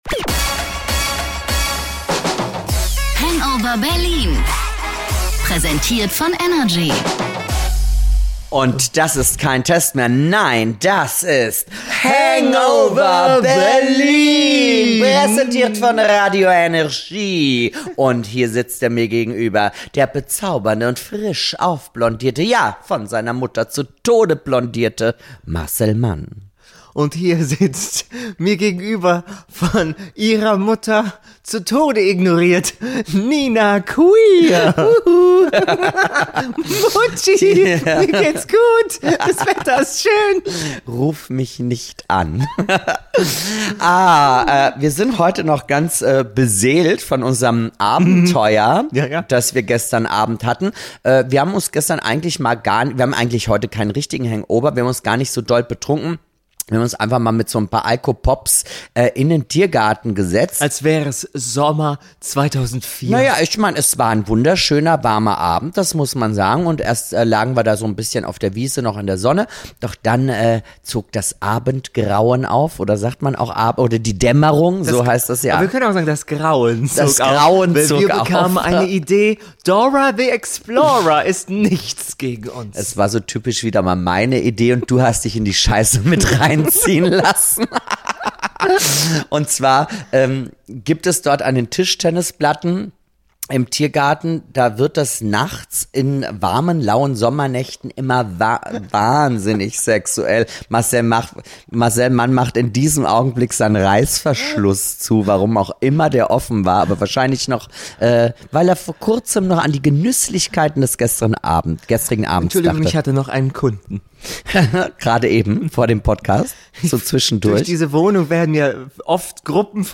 Im Berliner Tiergarten natürlich, dort haben unsere Lieblings Podcasterinnen geschwoft und über härtere Gerichtsfälle geredet als das Best Of Barbara Salesch.